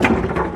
PixelPerfectionCE/assets/minecraft/sounds/tile/piston/out.ogg at bbd1d0b0bb63cc90fbf0aa243f1a45be154b59b4